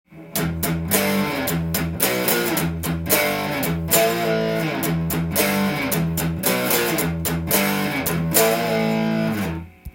Emペンタトニックスケールで例を作ってみました
パワーコード（１度と５度の和音）で構成され
８分と４分音符を使いミディアムテンポで弾いていきます。